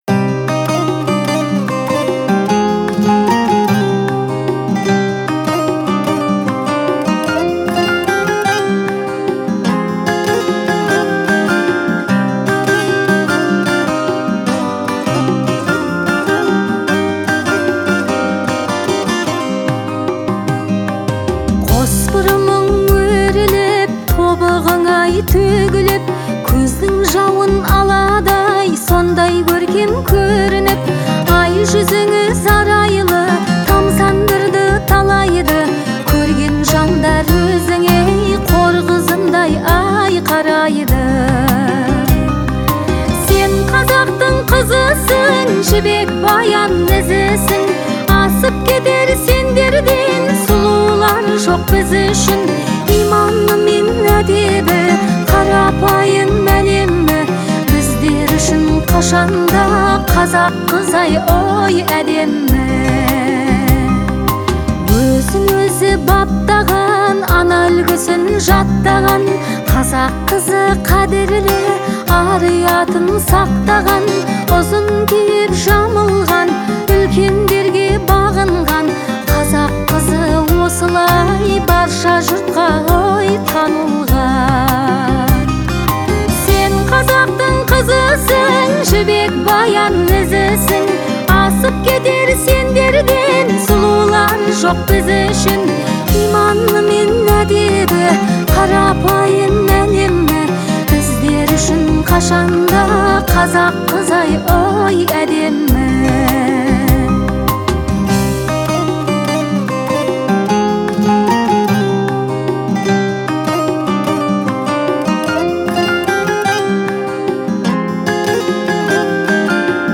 это яркий пример казахской поп-музыки